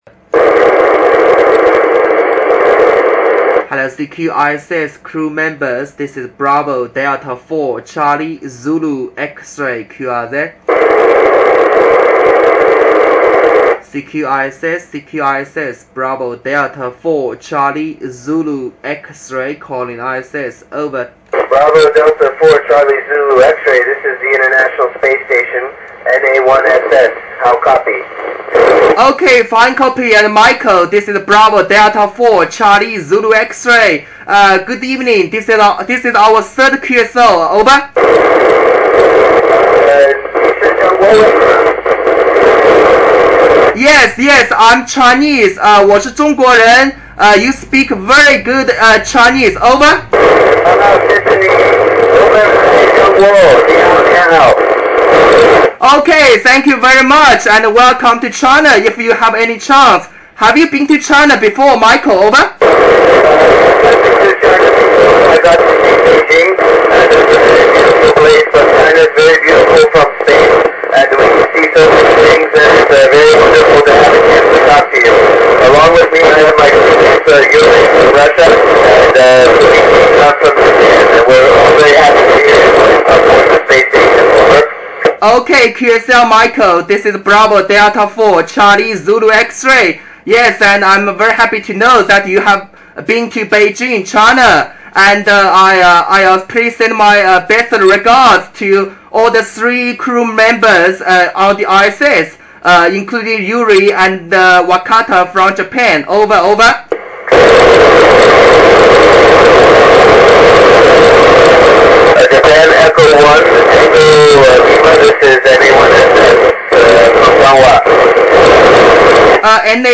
Location: Shanghai, China
Rig: FT-897
Antenna: X-510 Vertical 15mh